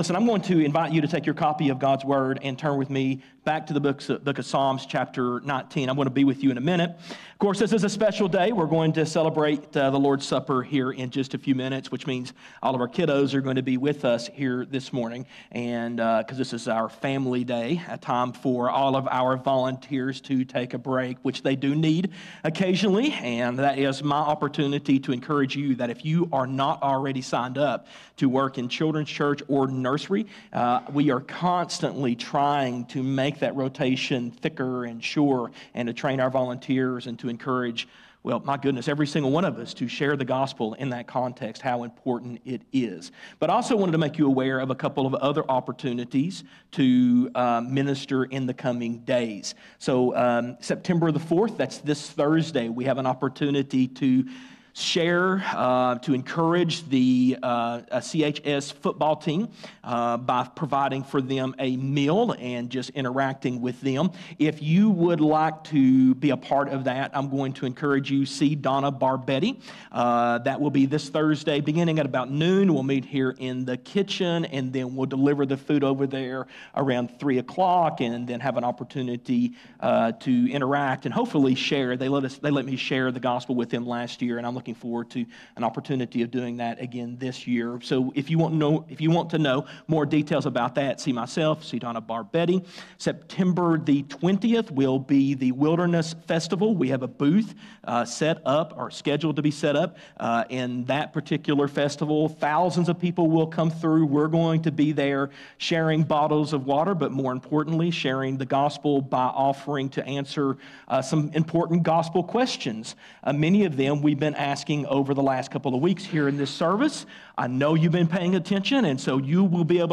A message from the series "Faith For A Reason."